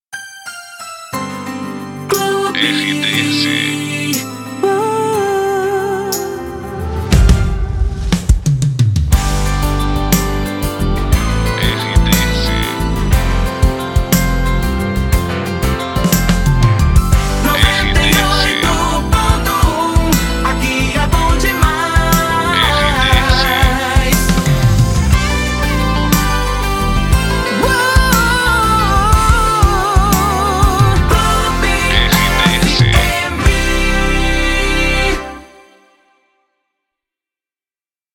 Entrada de Bloco